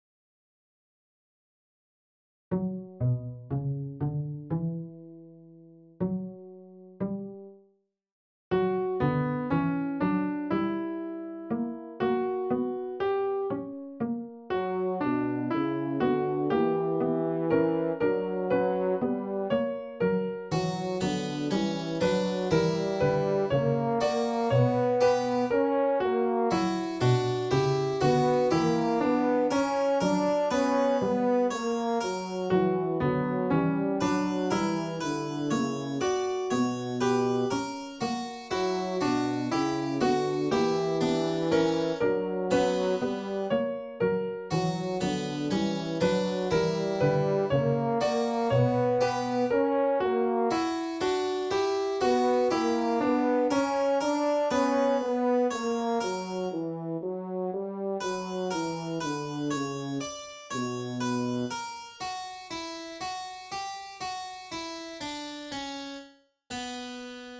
Kanon à 4 (B/A/T/S).
Elektronische Wiedergabe 4-stimmig z. B. mit dem Programm VLC media player: